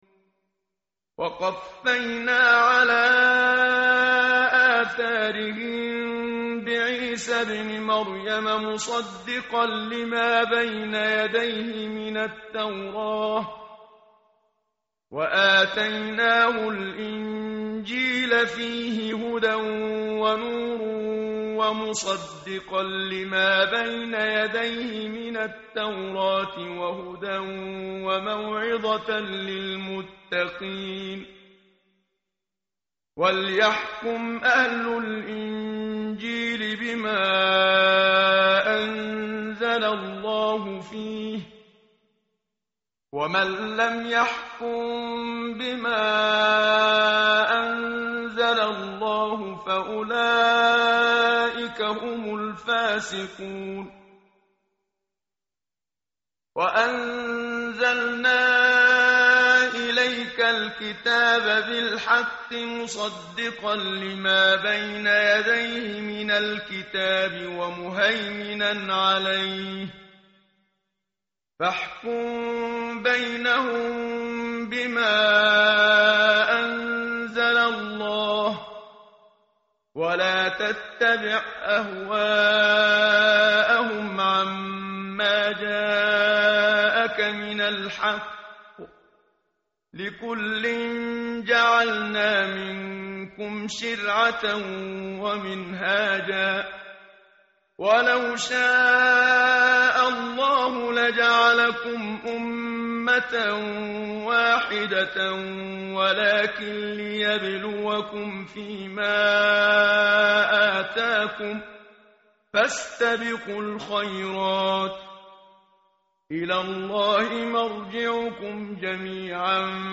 tartil_menshavi_page_116.mp3